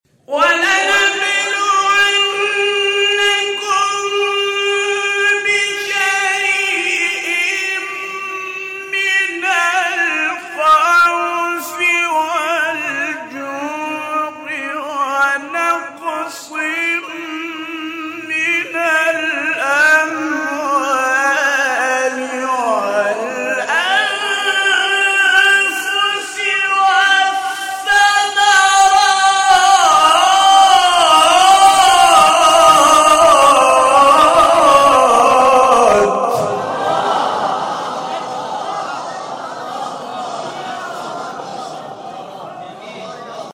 گروه شبکه اجتماعی: مقاطعی از تلاوت‌های صوتی قاریان برجسته کشور ارائه می‌شود.